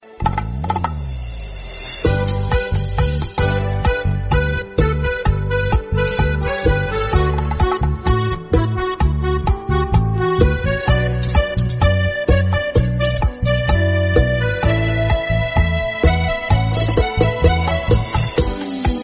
P O L I C E